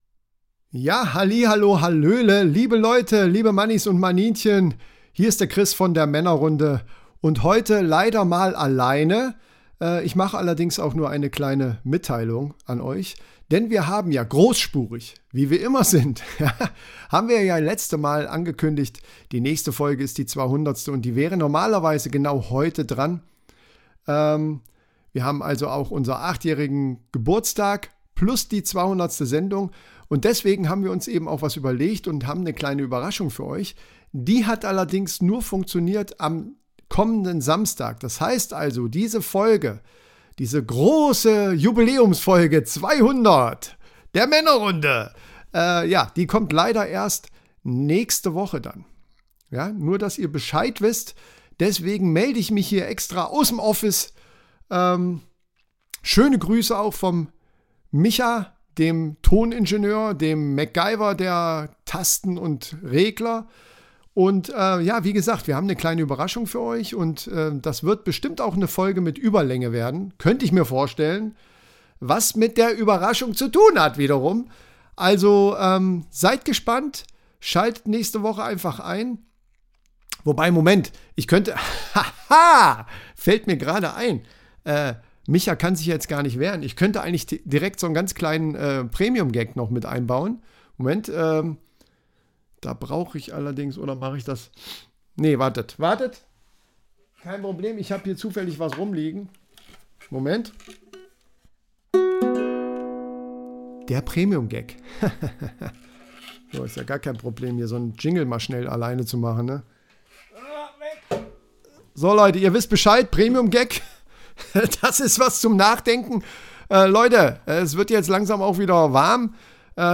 Genres: Comedy , Improv